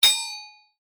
Sword Hit E.wav